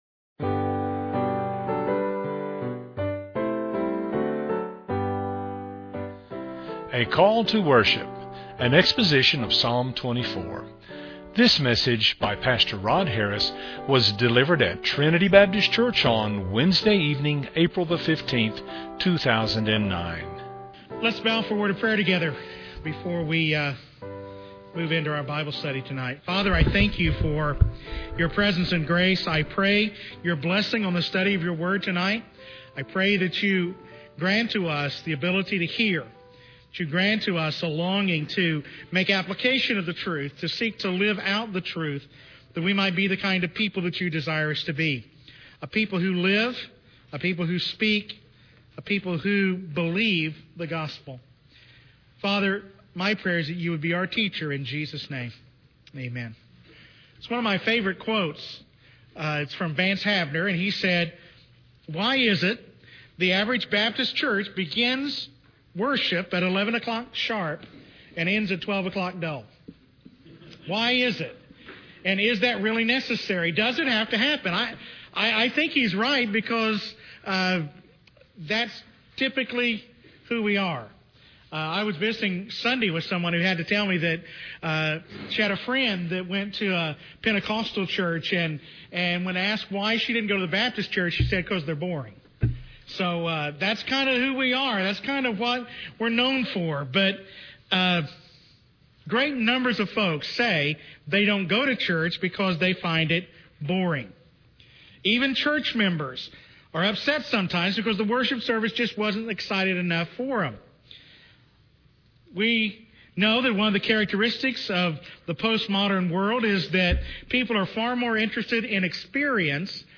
An exposition of Psalm 24.